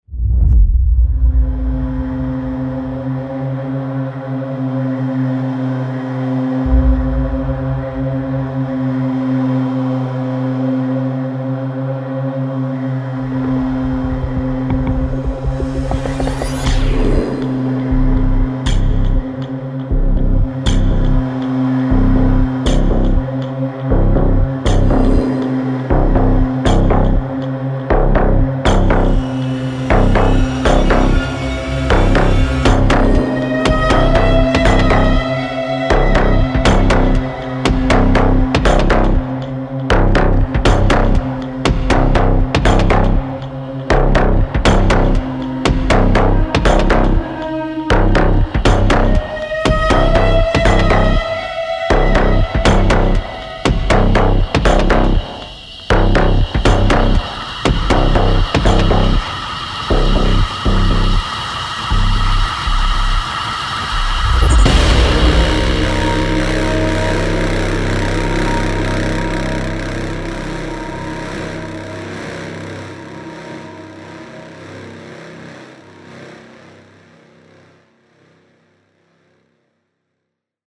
黑暗大气科幻氛围电影音乐制作人音效包 Ghosthack Sci Fi Atmospherics（5142）
该软件包分为10个不同的文件夹，提供了一个精心安排和听觉清晰的氛围，无人机，旋律以及超密集的纹理，以在听众的头脑中创造一个完整的世界。
最重要的是，我们有令人费解的低音和低音线，如果使用得当，它们会使房间里任何人的发型变平。结合超过50种强大的效果，冲击力，打击乐和酥脆的顶部，您绝对拥有一切，不仅可以为电影或视频游戏创建原创和非正统的配乐，还可以使其成为可以独立作为深沉氛围声音设计的漩涡。